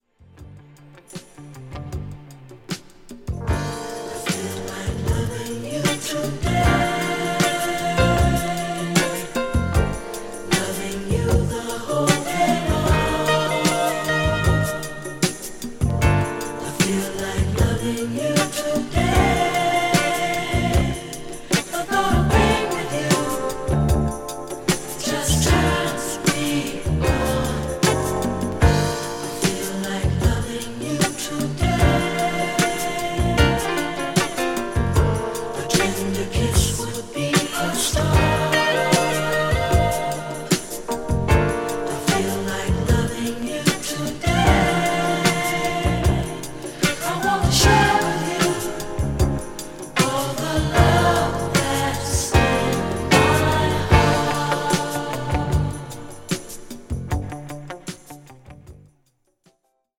FUSION